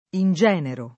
ingenero [ in J$ nero ]